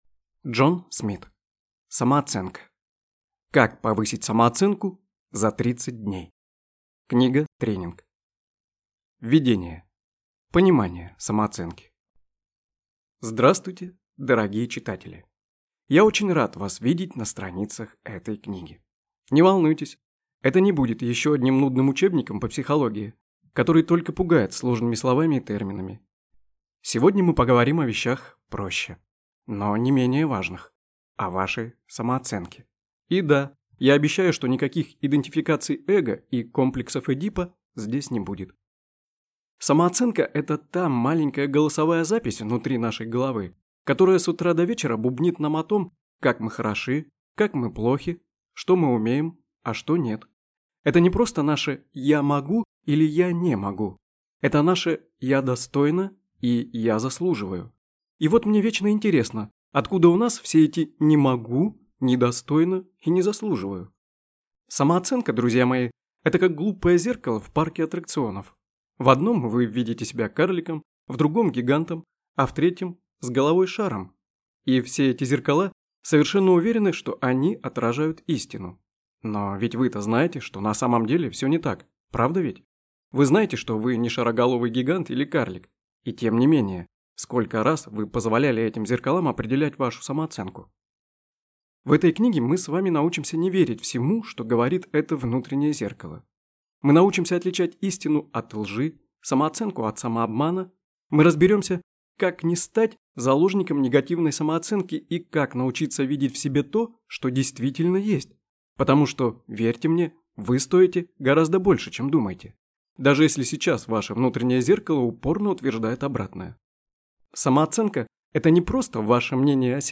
Аудиокнига Самооценка. Как повысить самооценку за 30 дней. Книга-тренинг | Библиотека аудиокниг